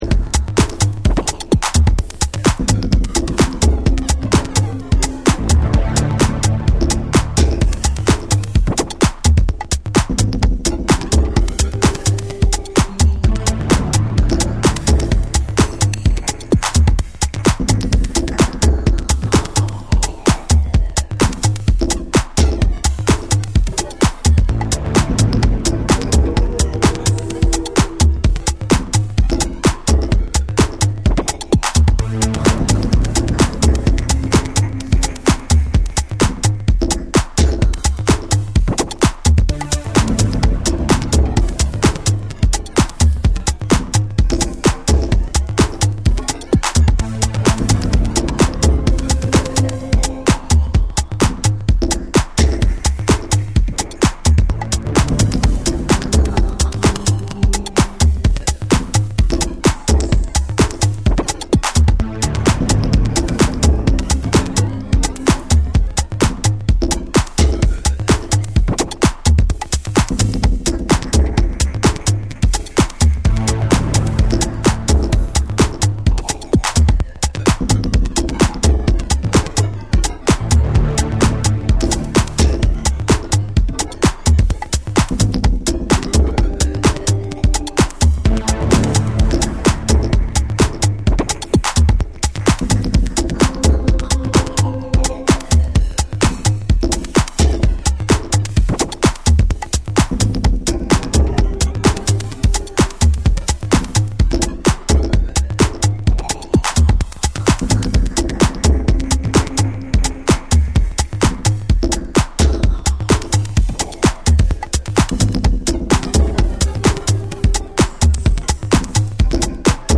ファンキーなベースラインとトライバルなリズムが絡み合うディープなミニマル・ハウス
軽やかで抜けの良いビートにファンク要素が映えるグルーヴィーな
TECHNO & HOUSE / ORGANIC GROOVE